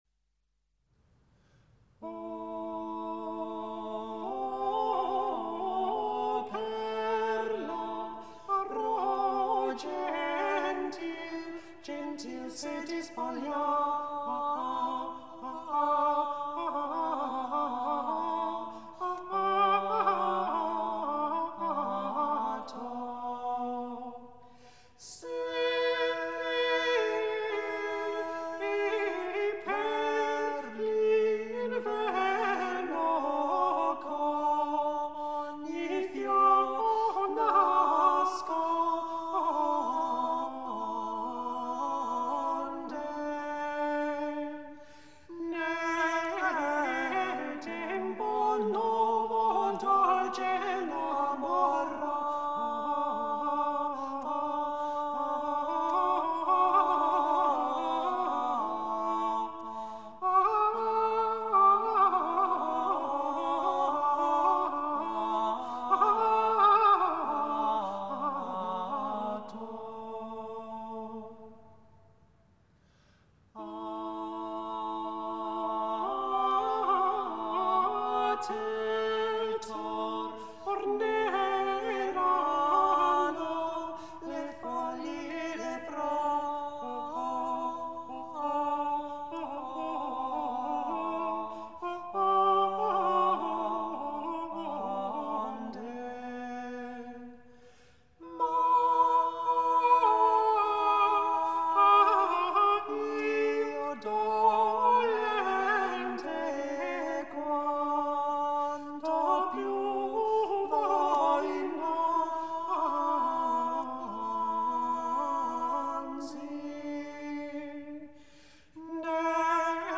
O perlaro gentil - Madrigale